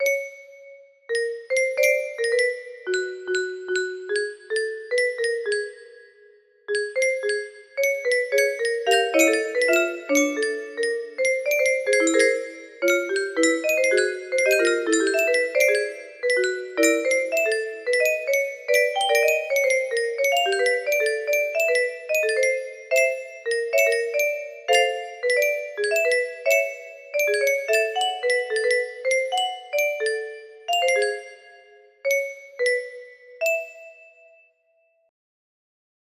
happy music box melody
happy